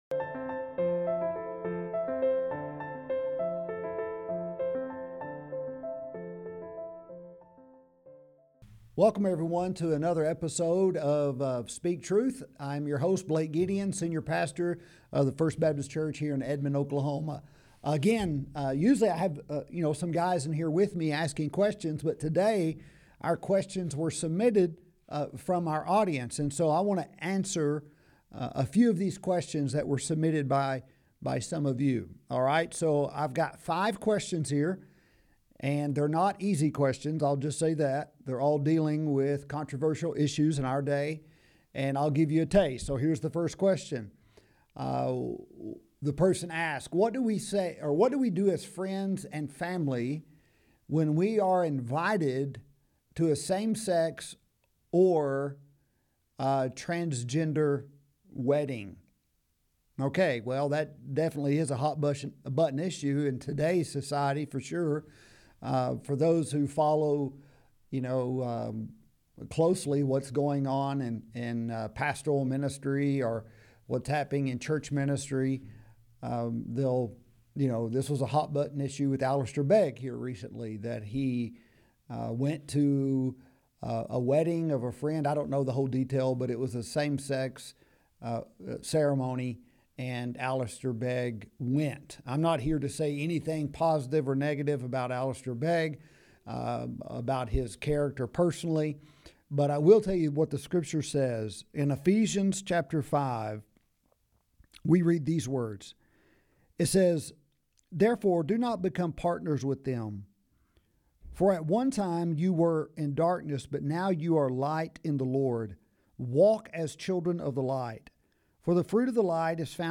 Ep. 14 Q&A